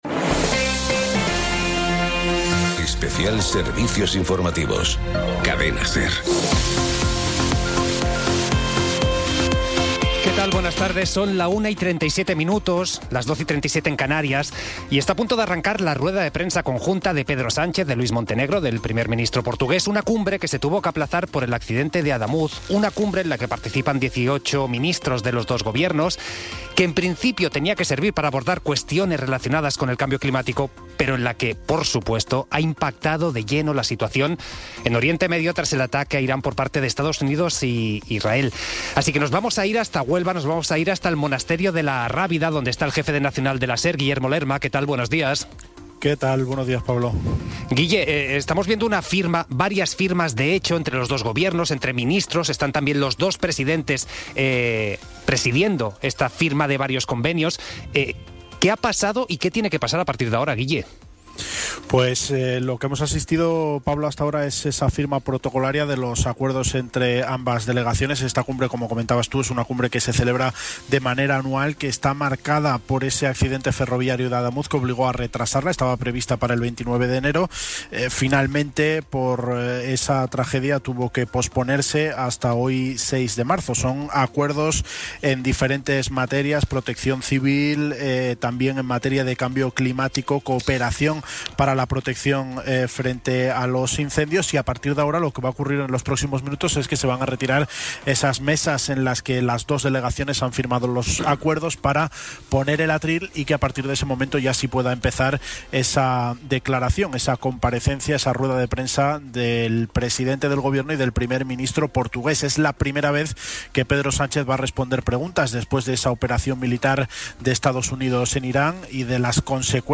Retransmisión en directo de la rueda de prensa de Pedro Sánchez desde La Rábida (Huelva) tras la Cumbre Hispano-Portuguesa con el primer ministro Luís Montenegro, donde ambos gobiernos sellan la Alianza por la Seguridad Climática. Análisis, contexto político y claves de una comparecencia marcada también por la tensión internacional y la agenda europea.